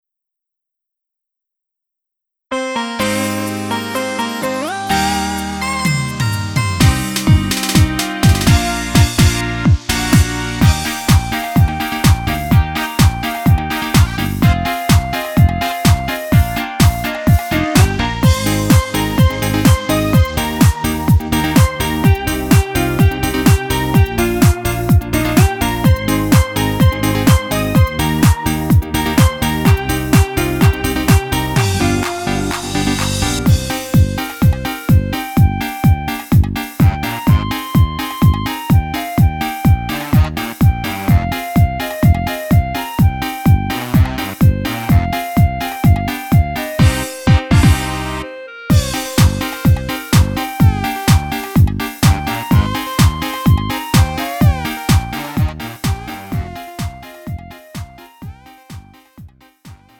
음정 원키 3:16
장르 가요 구분 Lite MR
Lite MR은 저렴한 가격에 간단한 연습이나 취미용으로 활용할 수 있는 가벼운 반주입니다.